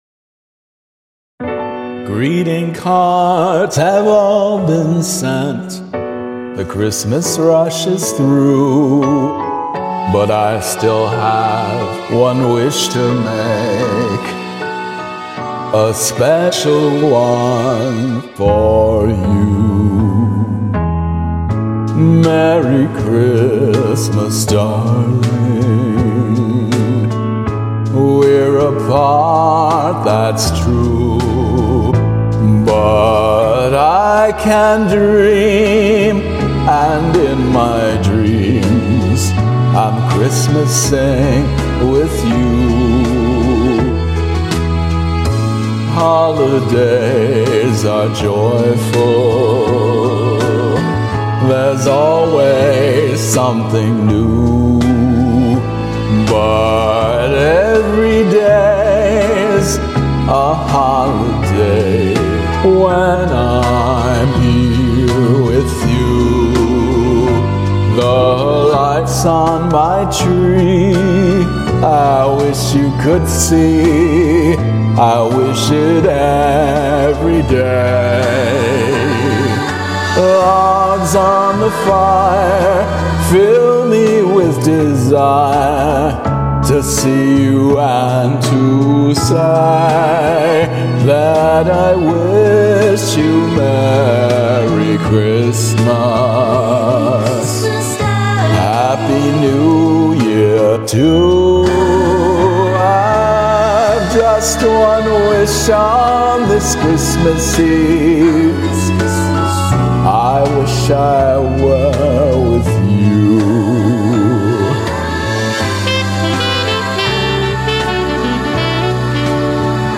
vocals
karaoke arrangement